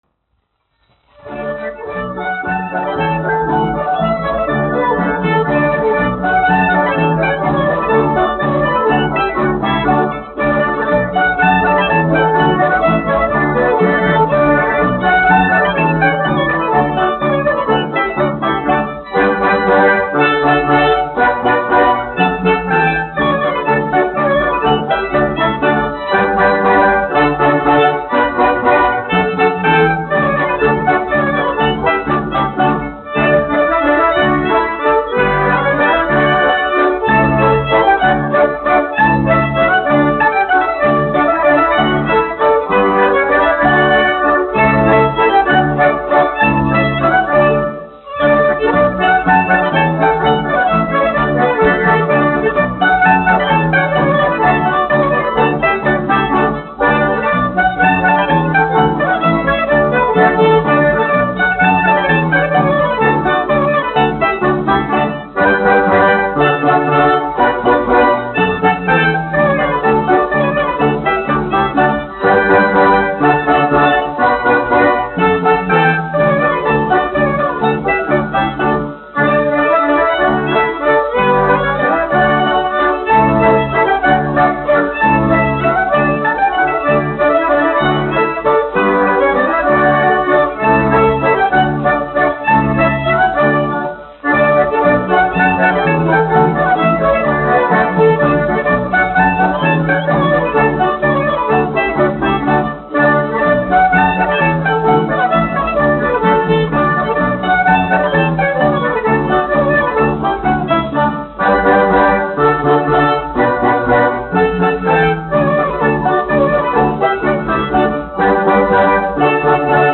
Puķīte pa dambi : tautas deja
1 skpl. : analogs, 78 apgr/min, mono ; 25 cm
Latviešu tautas dejas
Latvijas vēsturiskie šellaka skaņuplašu ieraksti (Kolekcija)